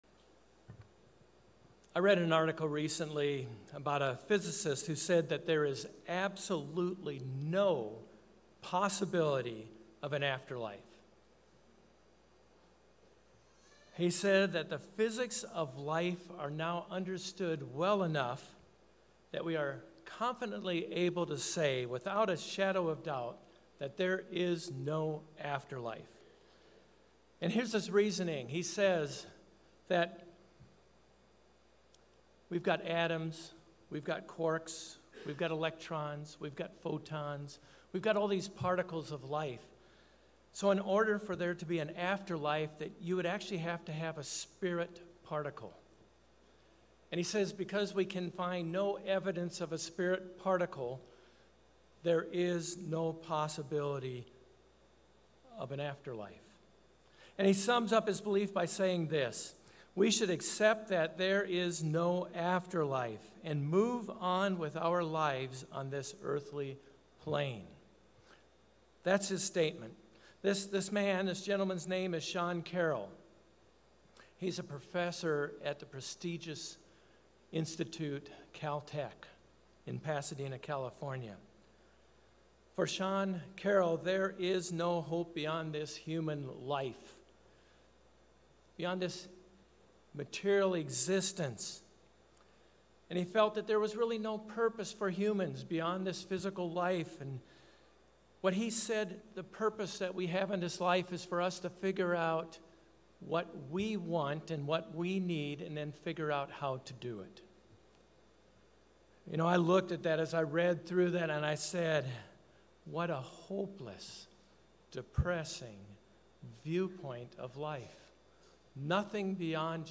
In this sermon we will look into God’s great plan as revealed by His Holy Days.